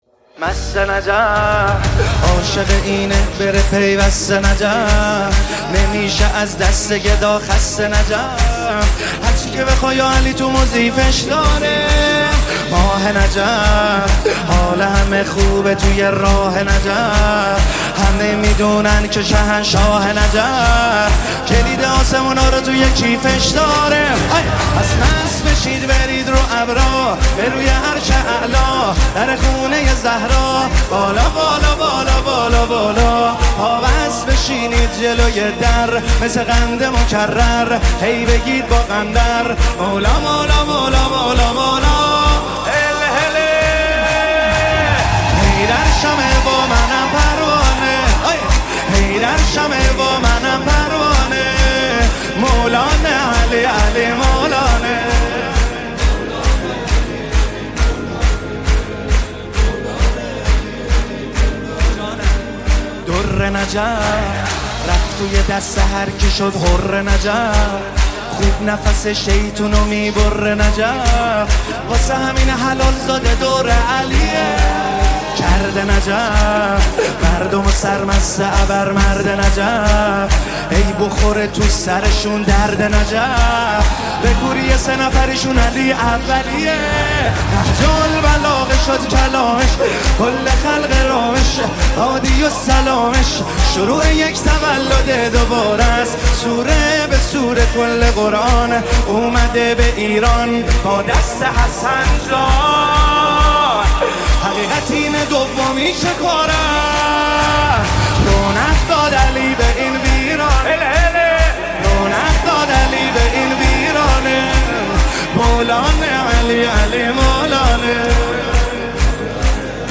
نماهنگ استودیویی